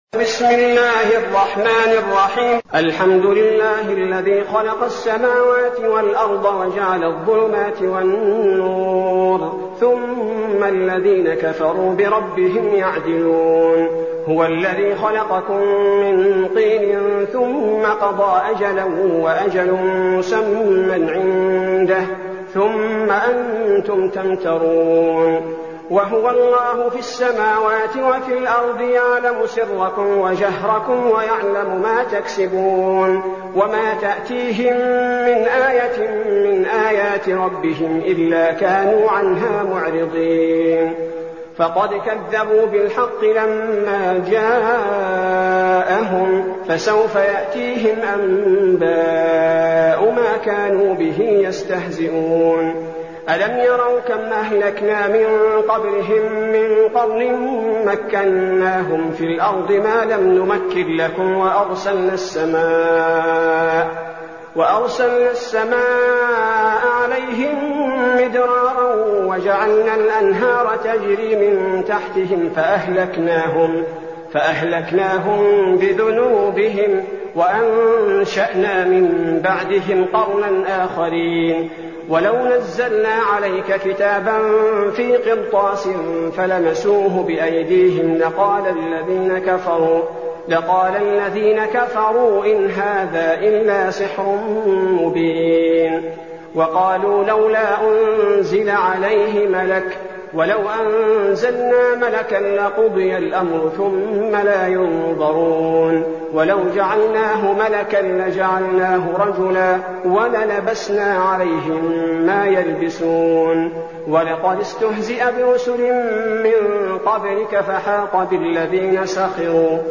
المكان: المسجد النبوي الشيخ: فضيلة الشيخ عبدالباري الثبيتي فضيلة الشيخ عبدالباري الثبيتي الأنعام